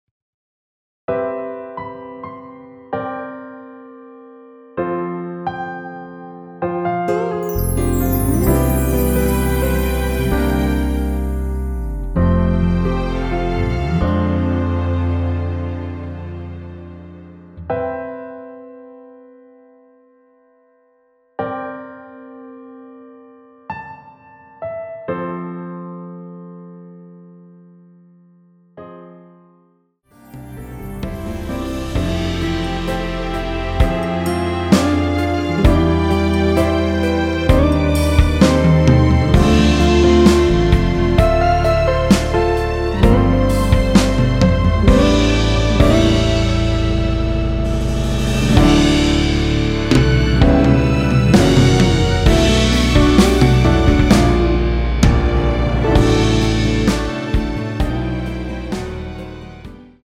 원키에서(-2)내린 MR입니다.(미리듣기 확인)
앞부분30초, 뒷부분30초씩 편집해서 올려 드리고 있습니다.
중간에 음이 끈어지고 다시 나오는 이유는